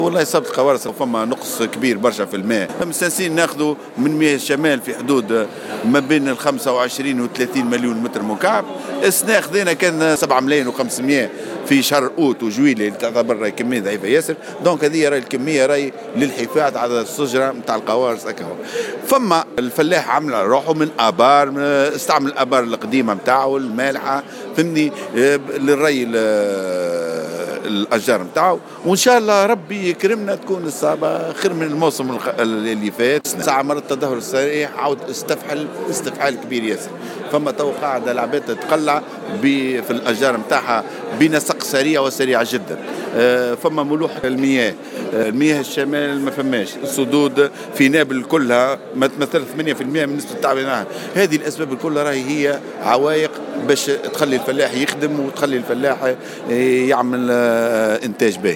Cap Bon: Le manque d’eau menace de la production des agrumes [Déclaration]